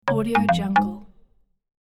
دانلود افکت صدای آهنگ زنگ چوبی رابط
• آرامش‌بخش و دلنشین: این صداها آرامش‌بخش و دلنشین هستند و به ایجاد فضایی آرام و دلنشین در پروژه‌های شما کمک می‌کنند.
• کیفیت صدای استودیویی: تمام صداها با بالاترین کیفیت ضبط شده‌اند تا بهترین تجربه صوتی را برای شما به ارمغان آورند.
16-Bit Stereo, 44.1 kHz